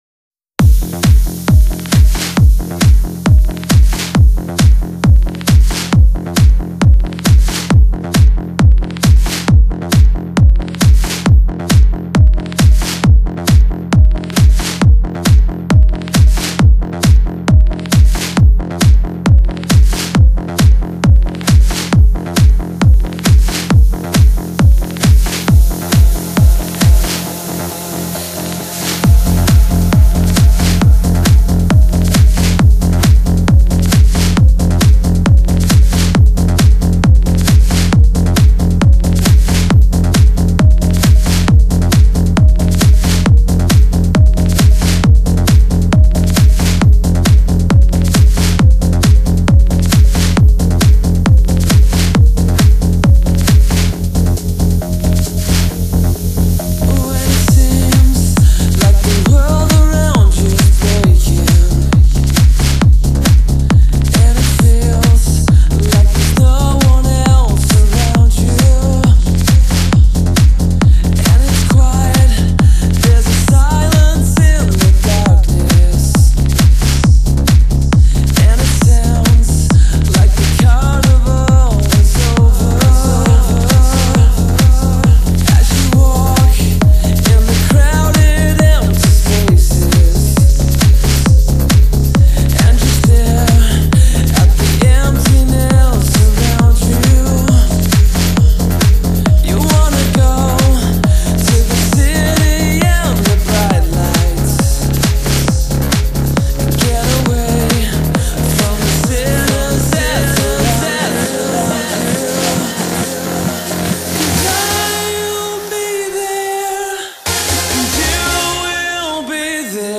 音乐类型: Trance